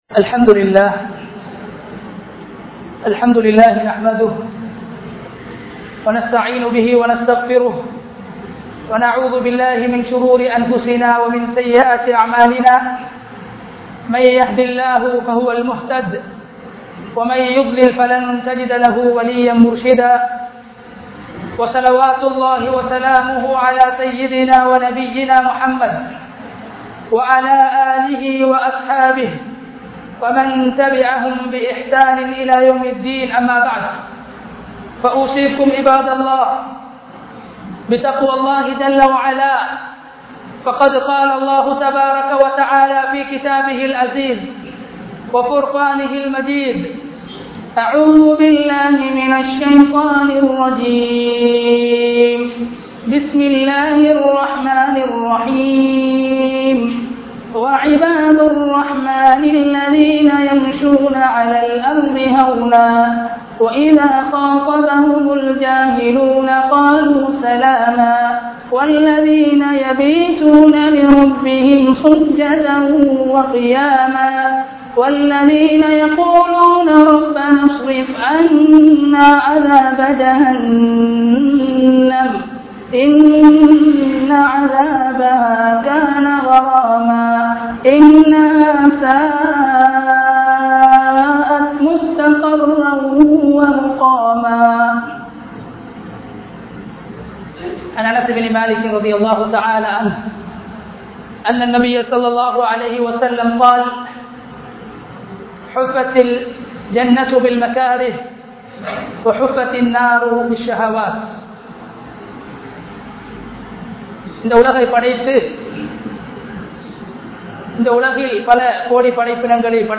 Al Quran Koorum Naraham (அல்குர்ஆன் கூறும் நரகம்) | Audio Bayans | All Ceylon Muslim Youth Community | Addalaichenai